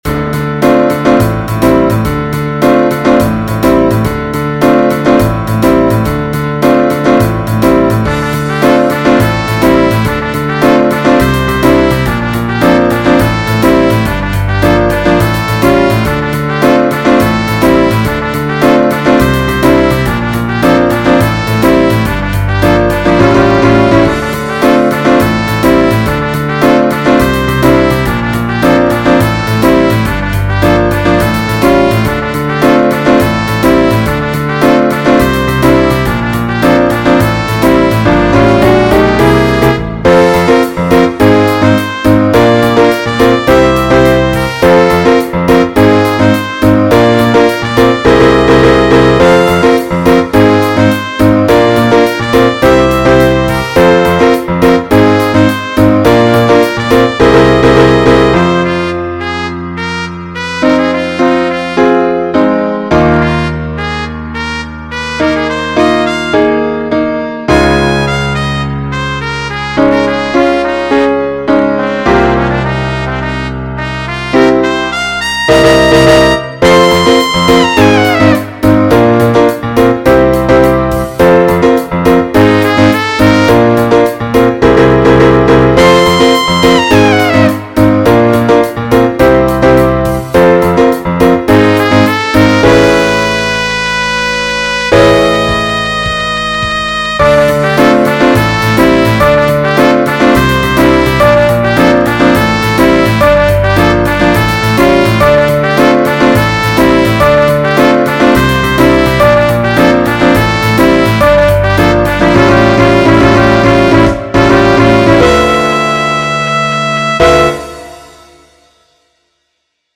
in voornamelijk een onregelmatige maatsoort. Een ideale wereld bestaat niet alleen uit een keurige 4/4 maat met een logisch akkoordenschema, maar er bestaan ook onregelmatige mensen en dingen in het leven.
Uiteindelijk komt alles in het stuk samen in de onregelmatige maatsoort, wat laat zien dat een ideale wereld bestaat uit regelmatige en onregelmatige mensen, die samenleven.